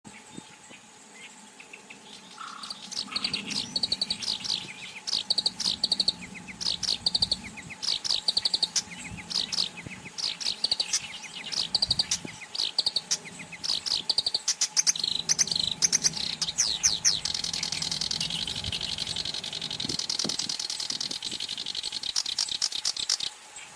ヨ シ キ リ 属   コヨシキリ １　　1-04-13
鳴 き 声：地鳴きは草の中でジッジッと鳴く。オオヨシキリより細い声でジョッピリリ、ジョッピリリ、ギョッギョキリキリ、チリリ等と早口で
鳴き声２